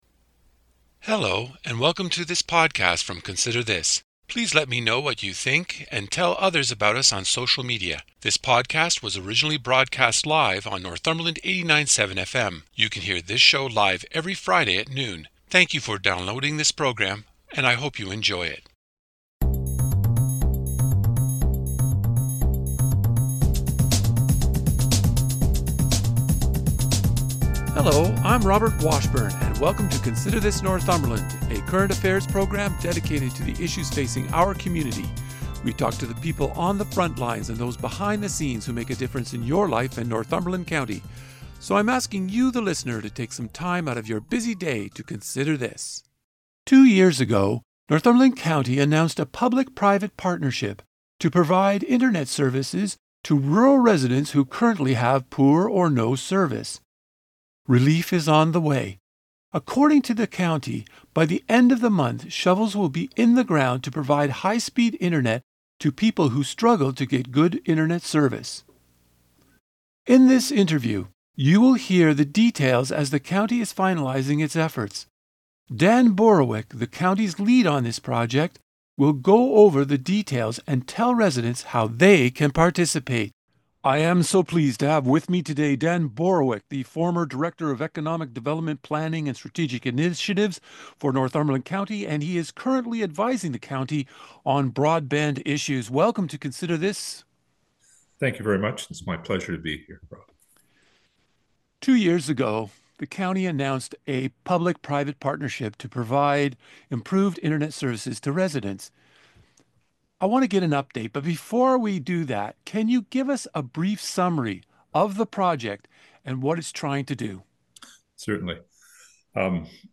In this interview, you will hear the details as the county is finalizing its efforts.